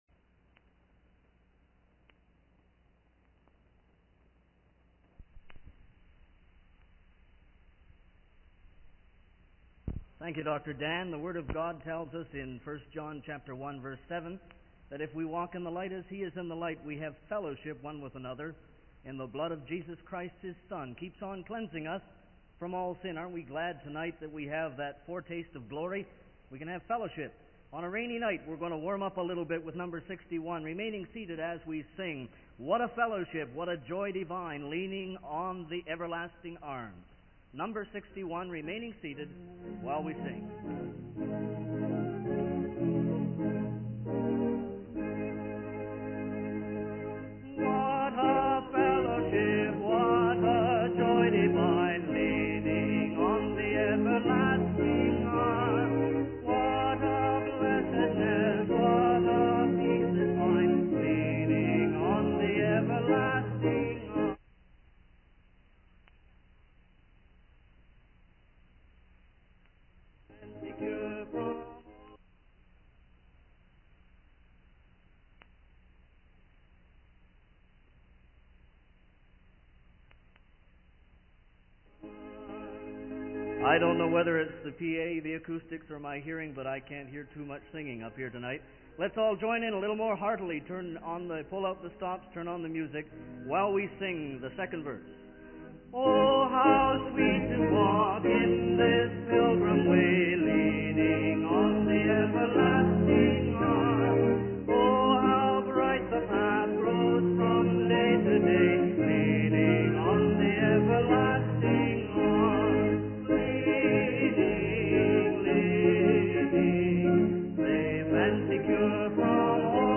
The speaker then leads the congregation in a hymn and encourages them to sing more enthusiastically. They emphasize the importance of walking in the light and having fellowship with one another through the cleansing power of Jesus' blood. The sermon concludes with a prayer for the sick and a request to remember those who have passed away.